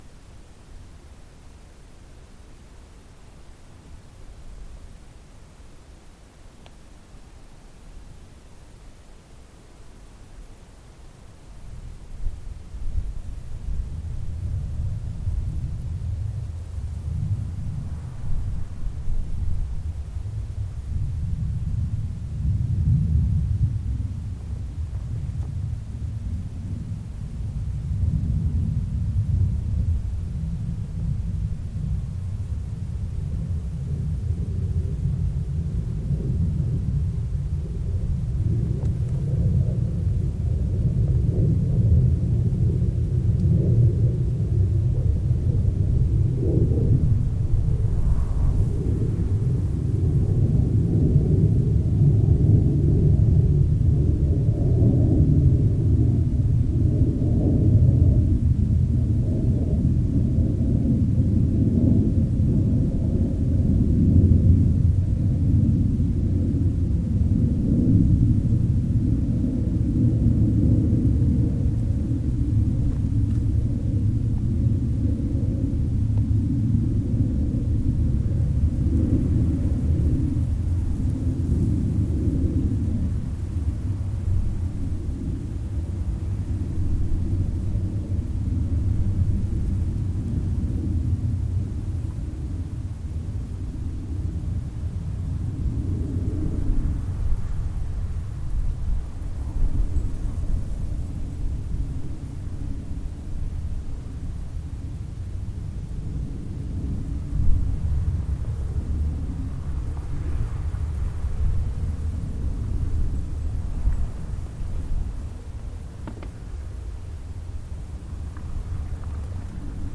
desert-plane.mp3